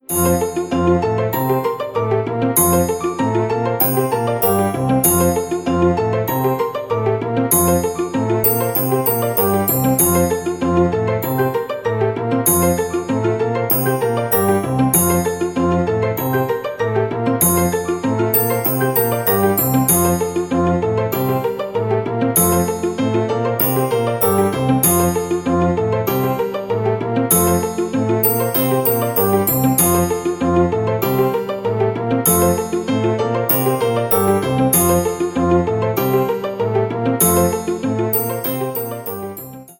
Мелодии на звонок